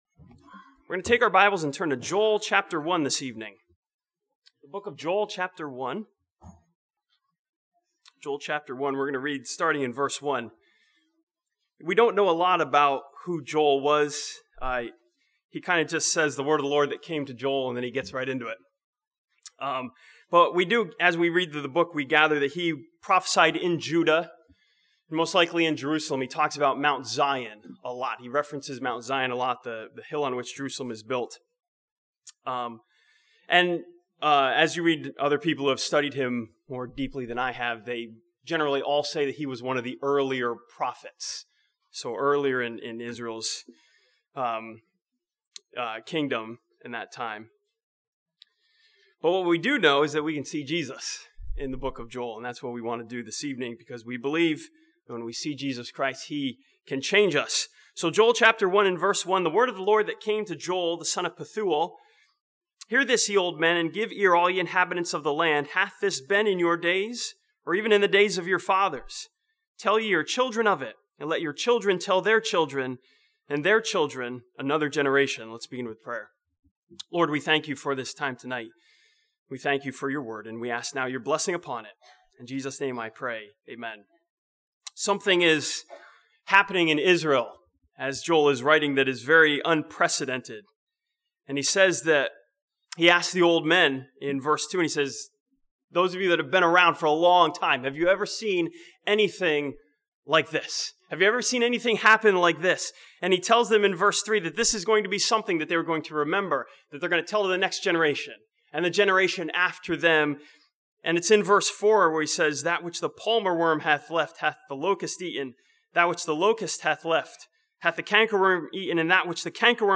This sermon from Joel chapter 2 sees Jesus Christ as our answer to all the intense questions that life throws at us.